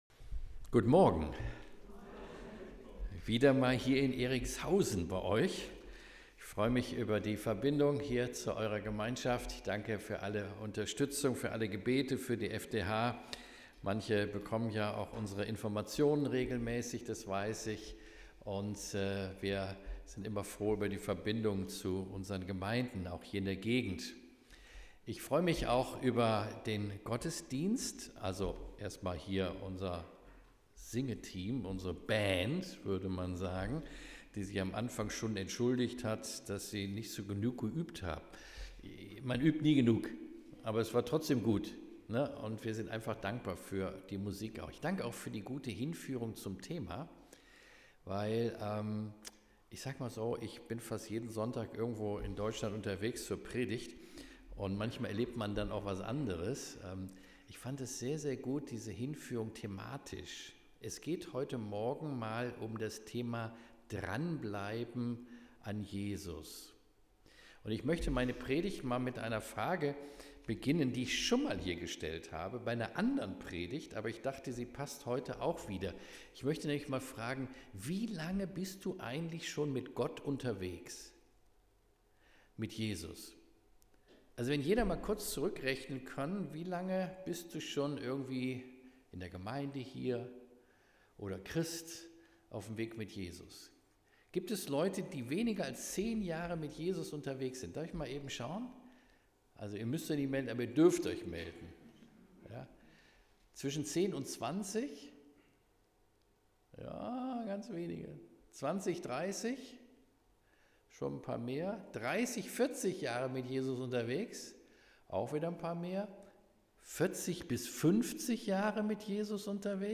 „Dranbleiben an Jesus“ Predigt